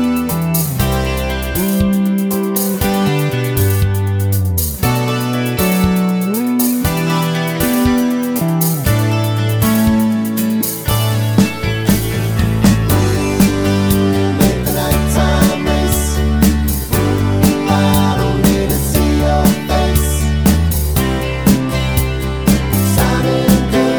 Male Key of F Pop (1970s) 3:38 Buy £1.50